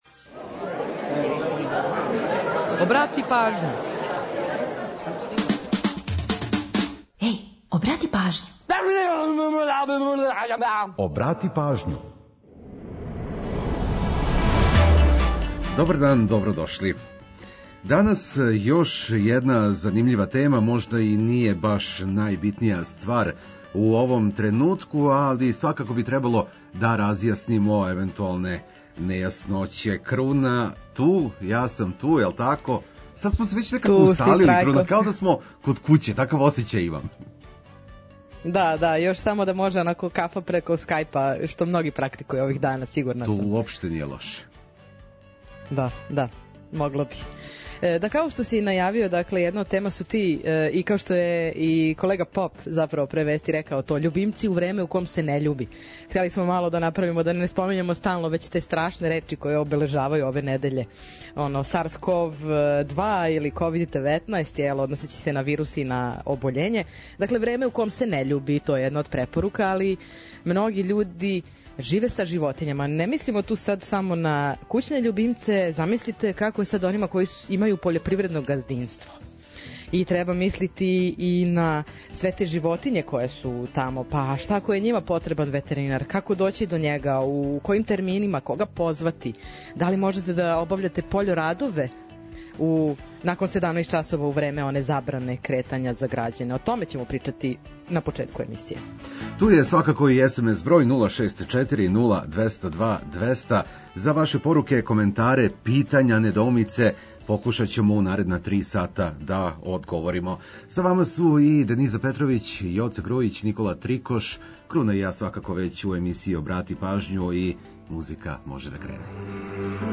За опуштеније теме, ту су приче иза песама, а прослављамо и рођендане албума, синглова и музичара. Ту је и пола сата резервисаних само за нумере из Србије и региона.